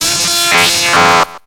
Cri d'Élektek dans Pokémon X et Y.